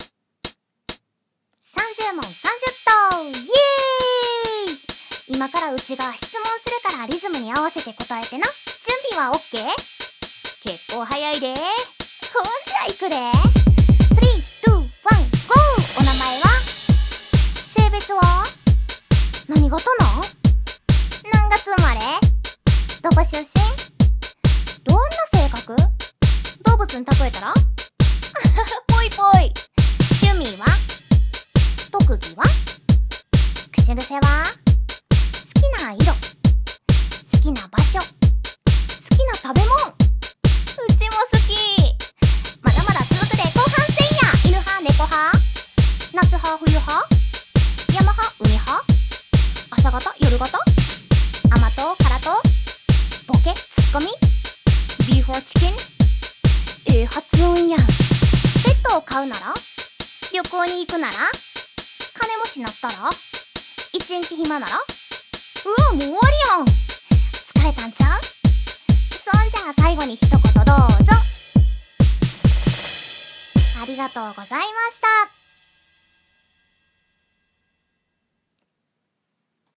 30問30答 関西弁で質問するで！